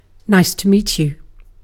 Ääntäminen
Synonyymit pleased to meet you Ääntäminen UK Haettu sana löytyi näillä lähdekielillä: englanti Käännöksiä ei löytynyt valitulle kohdekielelle.